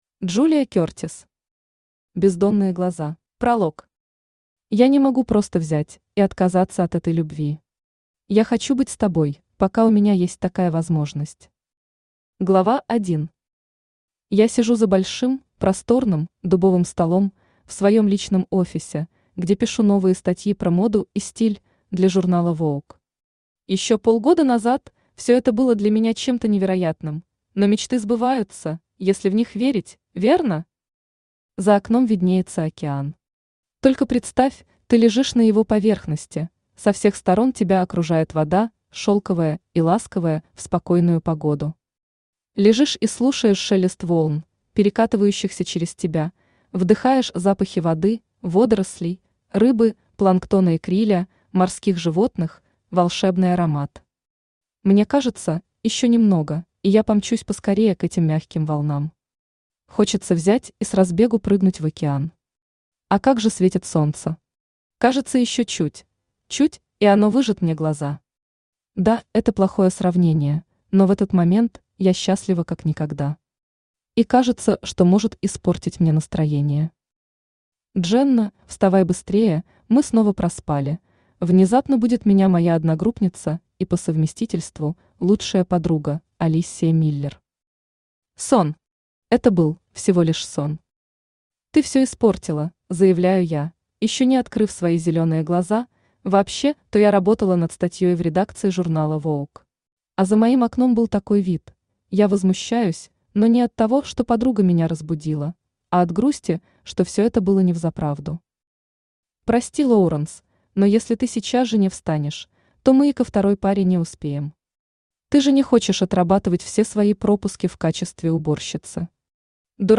Аудиокнига Бездонные Глаза | Библиотека аудиокниг
Aудиокнига Бездонные Глаза Автор Джулия Кертисс Читает аудиокнигу Авточтец ЛитРес.